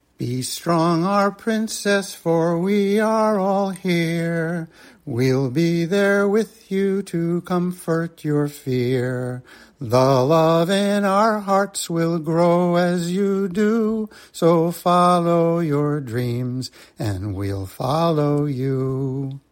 This entry was posted in A to Z Blog Challenge 2025, Original Song, Original Stories, Writing and tagged , , .
Rowans-support-lullaby-vocal.mp3